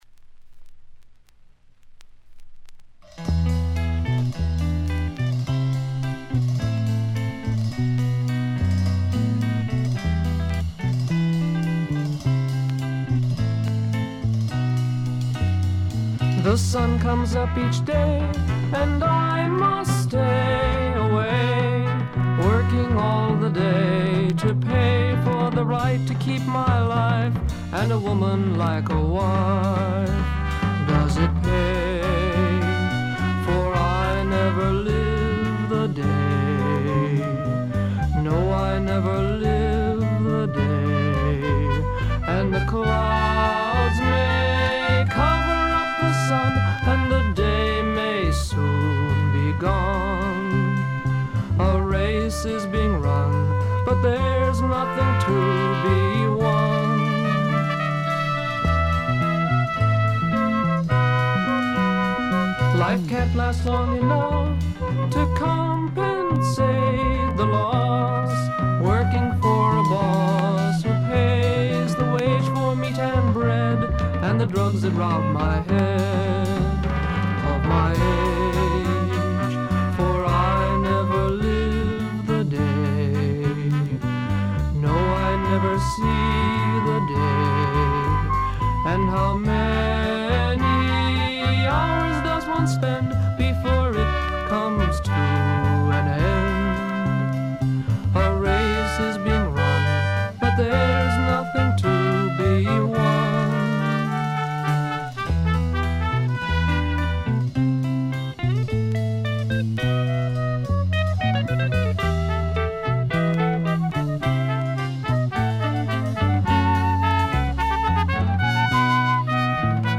昔からアシッドフォークの定番扱いされてきた名盤です。
フォーキーな曲からプログレッシヴでアヴァンギャルドな展開まで、一大サイケデリック絵巻を見せる名作と言ってよいでしょう。
試聴曲は現品からの取り込み音源です。